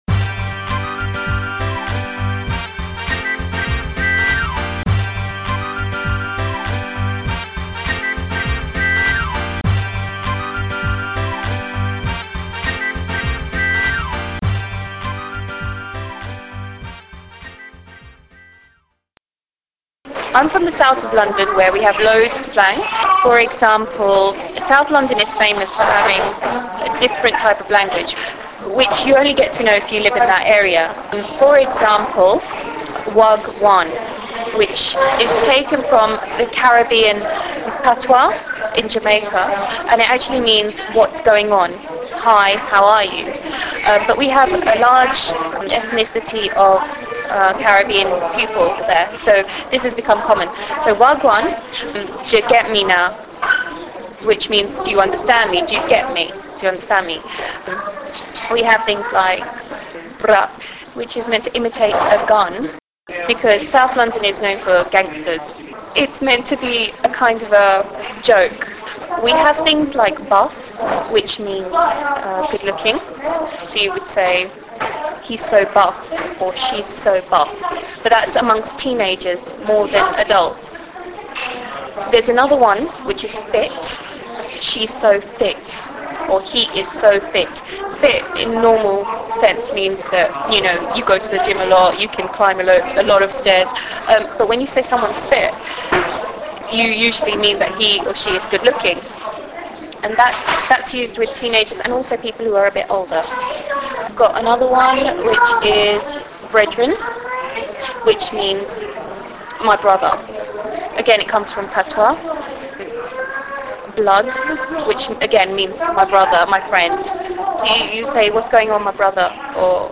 interview: slang